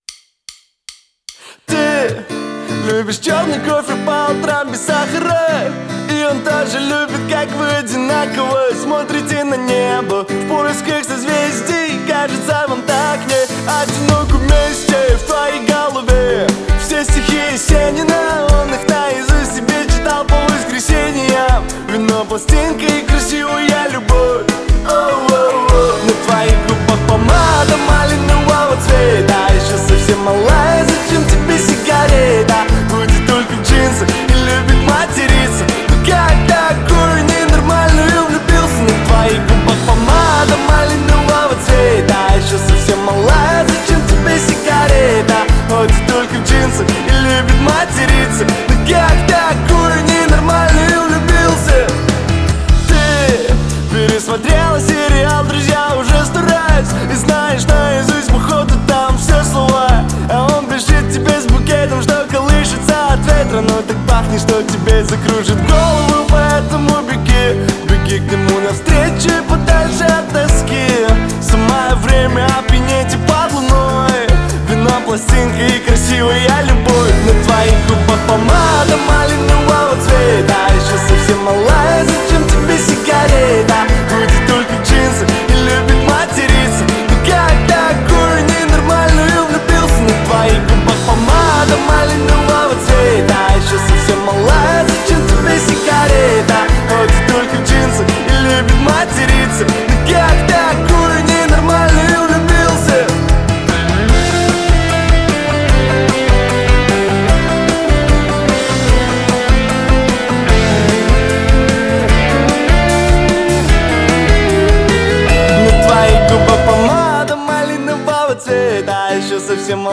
это яркий пример поп-рока